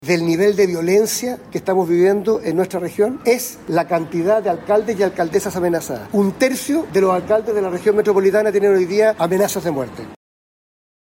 Las declaraciones se dieron en el contexto de un acuerdo de seguridad entre Santiago y Recoleta, instancia en la que las autoridades coincidieron en que el combate al delito requiere coordinación intercomunal, pero también mayores garantías de protección para quienes encabezan las acciones contra el crimen organizado en los territorios.